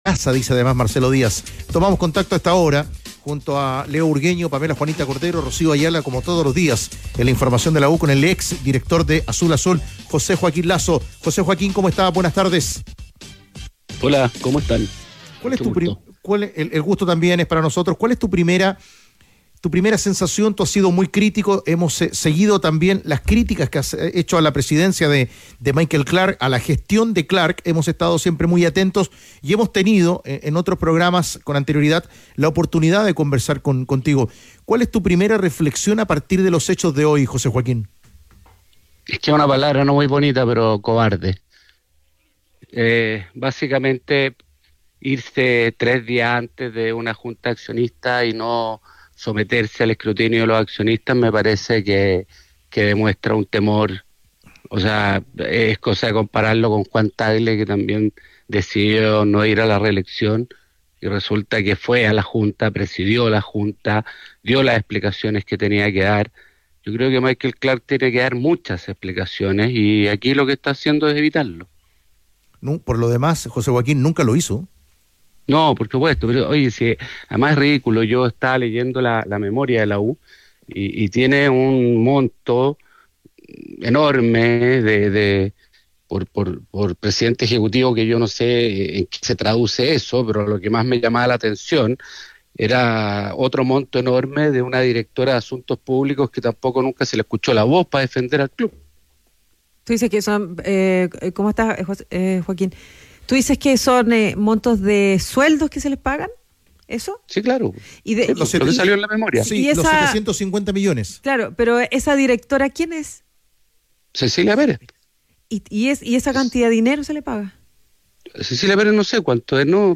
En conversación con Los Tenores de la Tarde